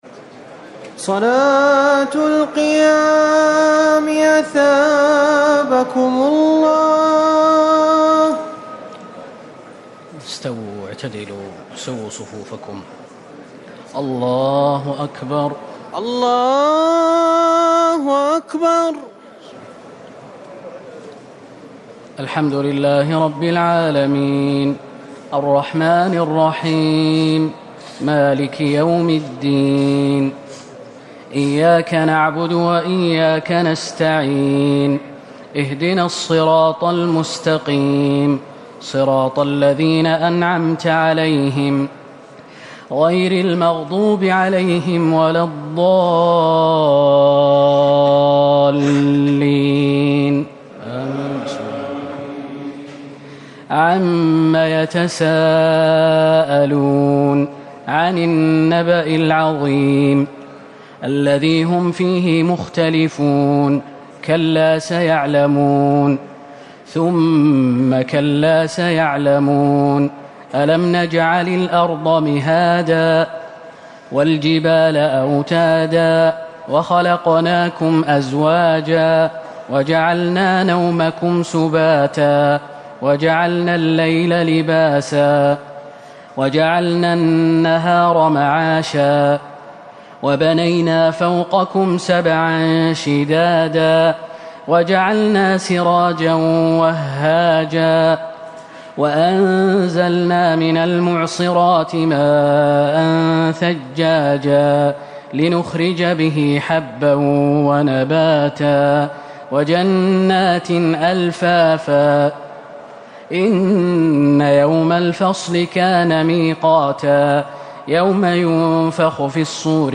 تراويح ٢٩ رمضان ١٤٤٠ من سورة النبأ - الطارق > تراويح الحرم النبوي عام 1440 🕌 > التراويح - تلاوات الحرمين